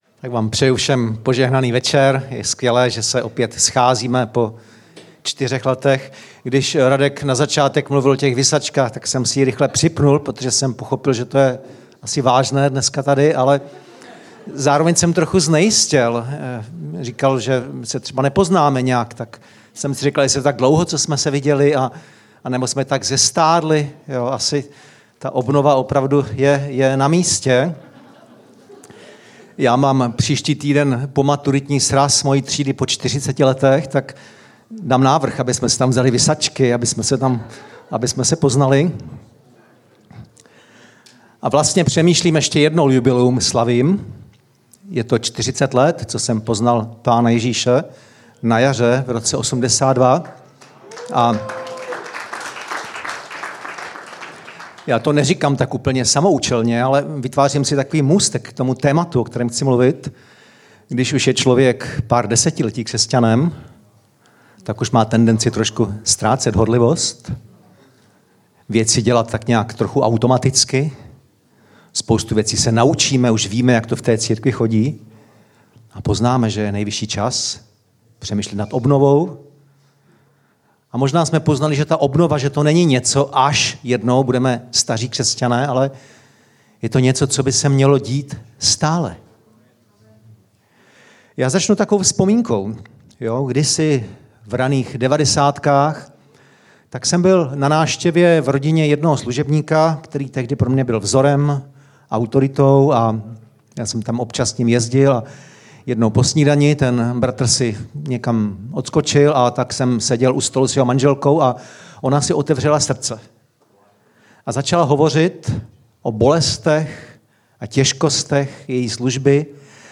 Československá pastorálna konferencia 2022 - Luhačovice